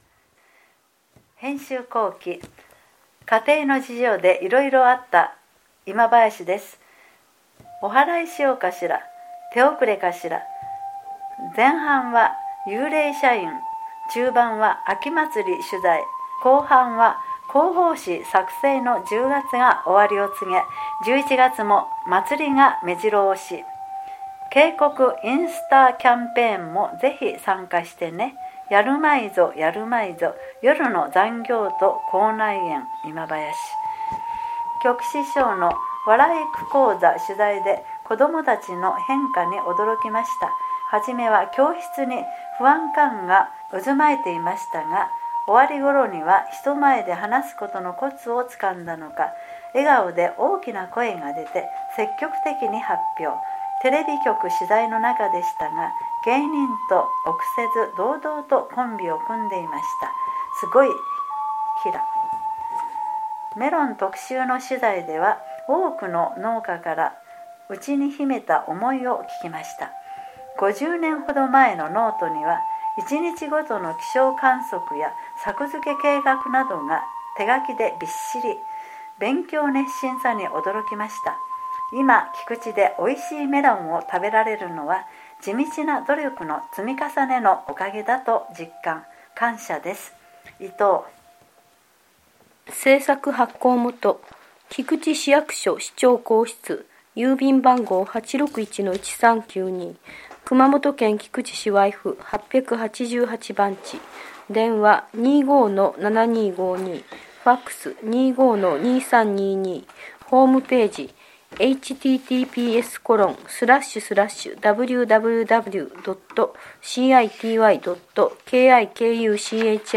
音訳
音訳は、「ひこばえ輪輪会」さんが行っています。